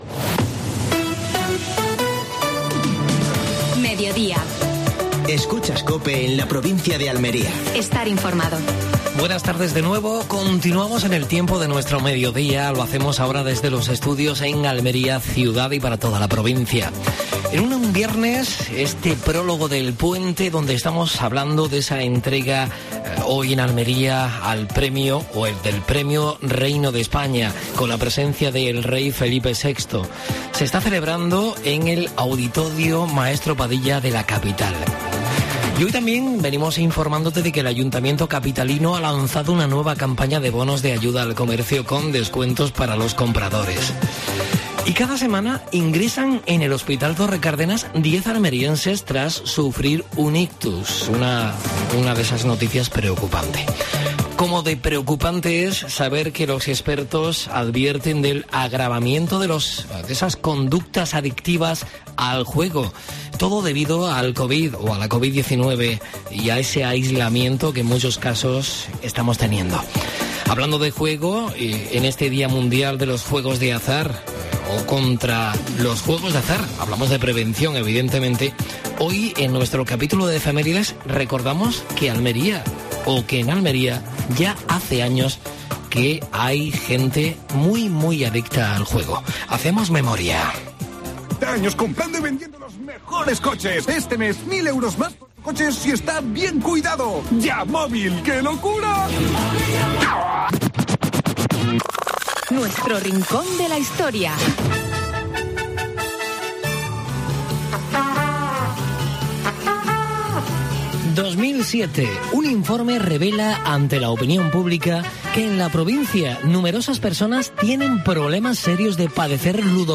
Actualidad en Almería. Entrevista a Puri Mata (concejala de Hacienda, Igualdad y Juventud del Ayuntamiento de Huércal de Almería). Última hora deportiva.